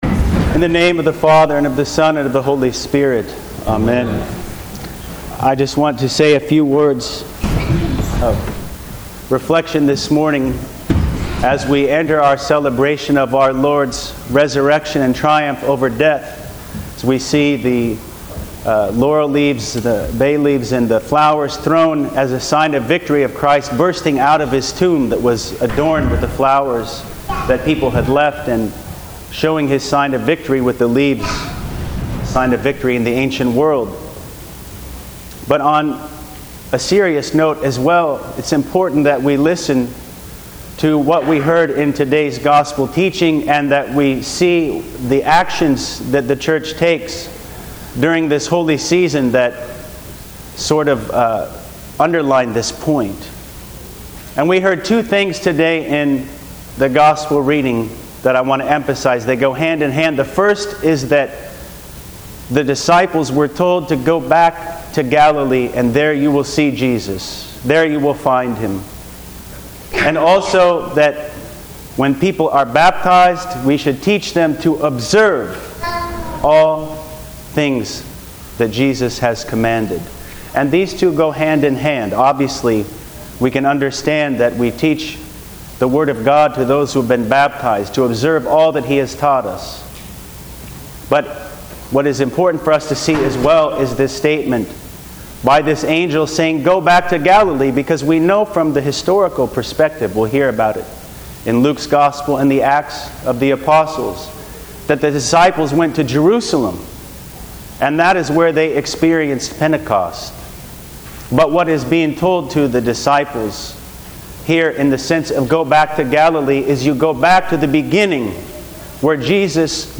Sermon: March 17, 2024: Forgiveness Sunday (Cheesefare)